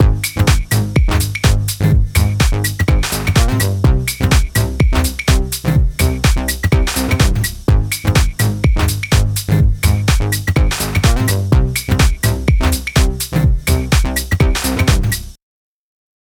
The drum and bass parts.
My idea currently consists of a drum loop and bass part.